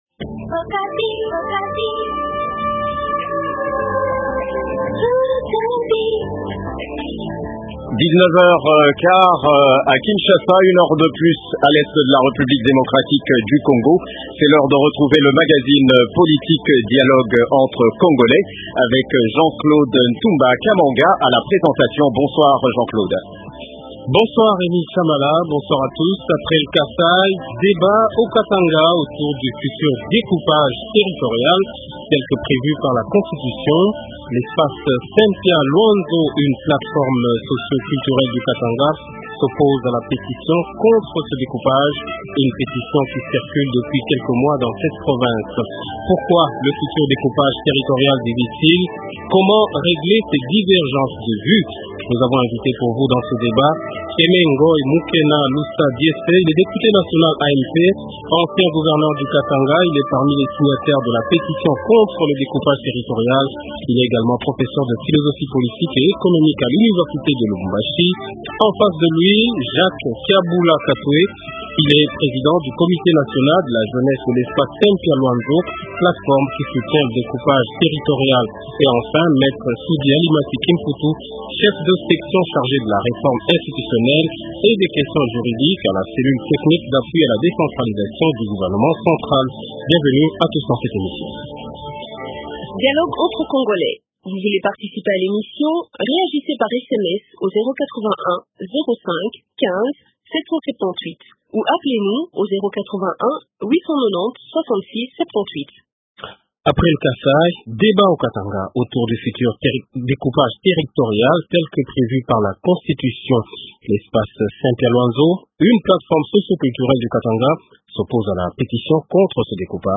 Après le Kasaï, débat au Katanga autour du futur découpage territorial tel que prévu par la constitution : l’espace Sempya-Lwanzo, une plate forme socio- culturelle du Katanga s’oppose à la pétition contre ce découpage